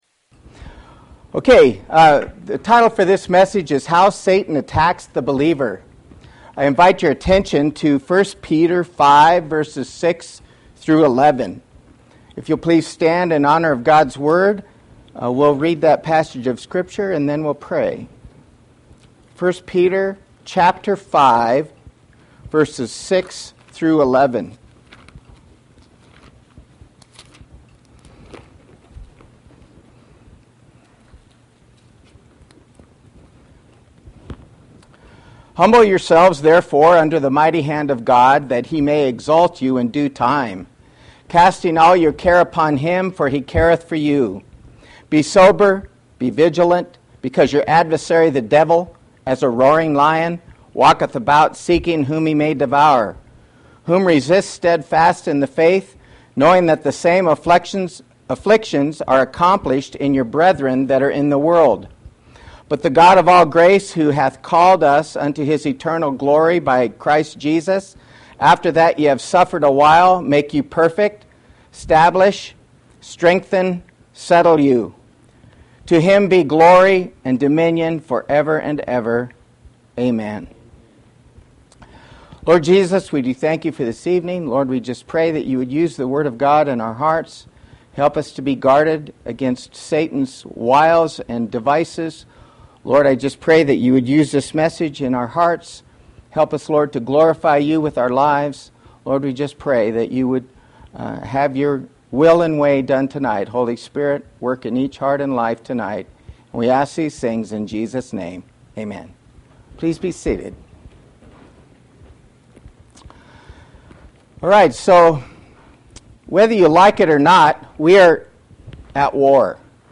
Service Type: Thursday Evening